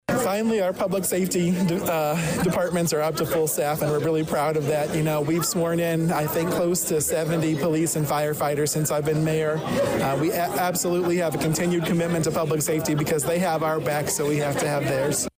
A full house in the Danville City Council Chambers on President’s Day (Feb 19th) morning witnessed the swearing in of three new Danville probationary firefighters.
For Mayor Rickey Williams, Jr; it was another great moment of Danville residents stepping up to serve; especially when it comes to the police and fire departments.